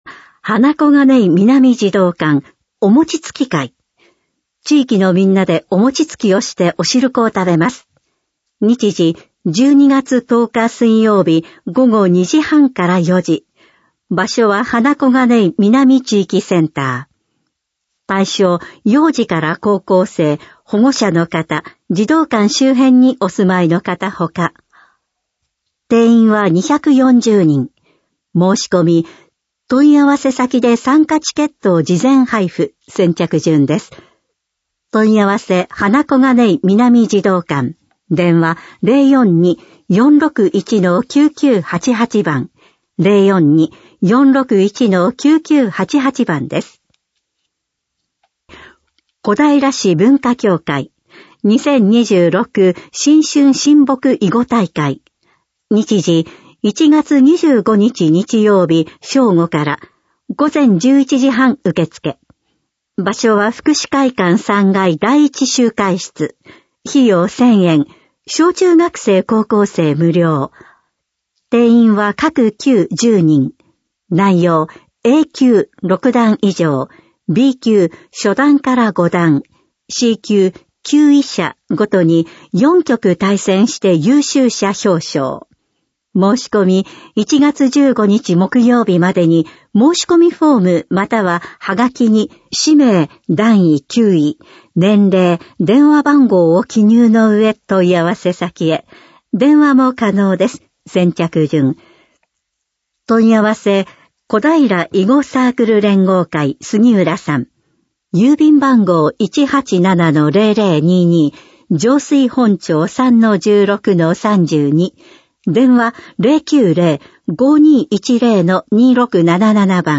市報こだいら2025年12月5日号音声版